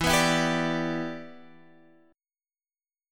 Listen to Em7 strummed